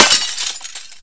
q3rally/baseq3r/sound/breakable/glass.ogg at 00bf6222a43540cfe9e41554ba65c17e54f8f839
glass.ogg